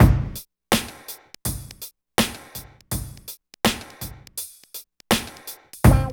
134 DRM LP-R.wav